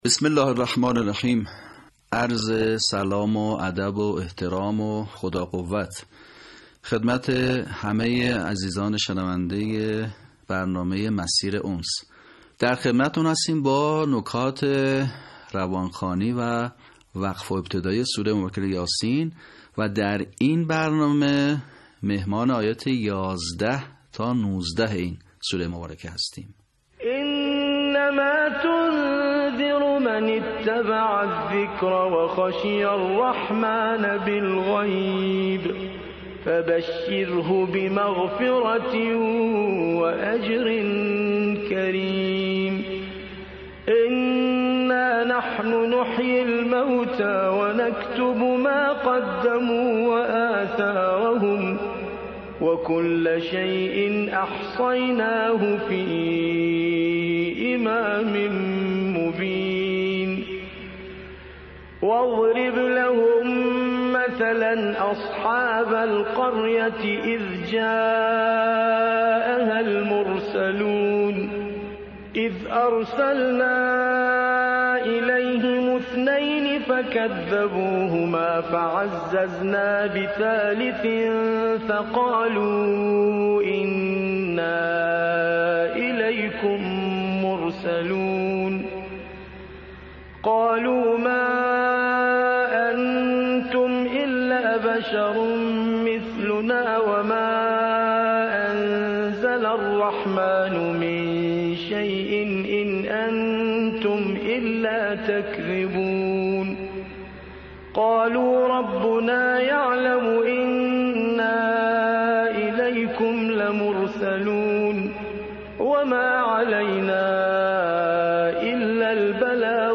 صوت | صحیح‌خوانی آیات ۱۱ تا ۱۹ سوره یس
به همین منظور مجموعه آموزشی شنیداری(صوتی) قرآنی را گردآوری و برای علاقه‌مندان بازنشر می‌کند.